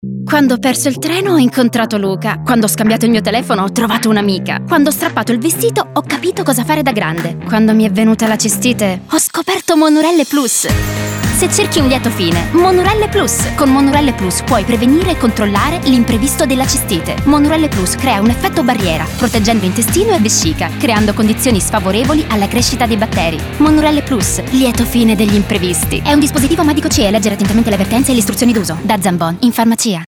Ascolta lo spot radio!
spot_Monurelle_Plus.mp3